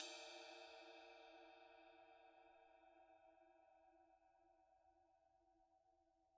susCymb1-hitstick_pp_rr2.wav